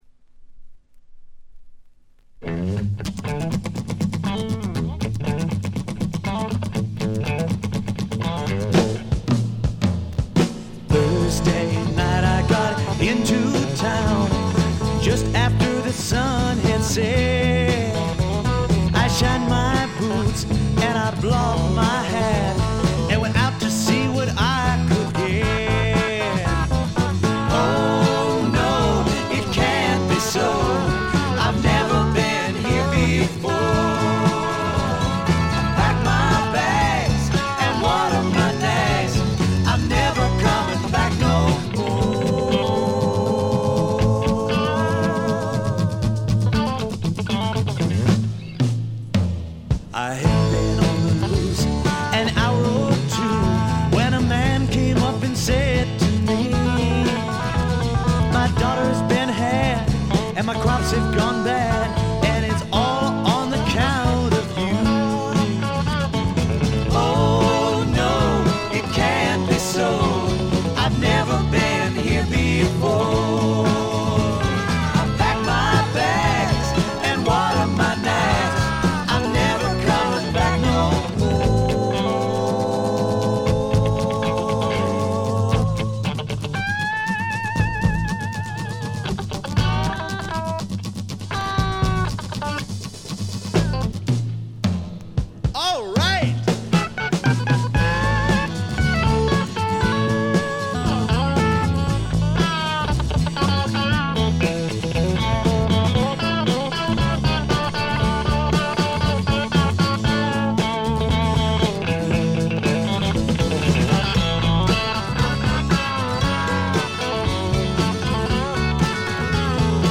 静音部での軽微なチリプチ少々。
英国シンガー・ソングライター基本中の基本。
試聴曲は現品からの取り込み音源です。